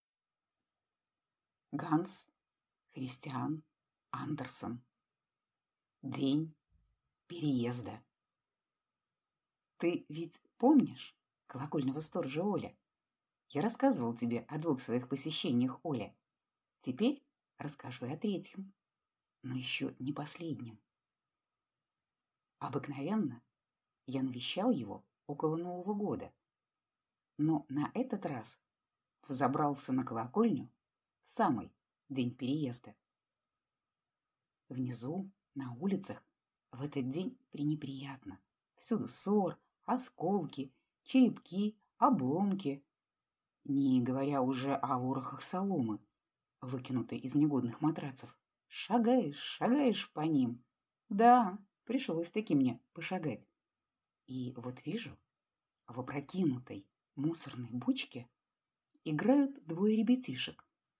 Аудиокнига День переезда | Библиотека аудиокниг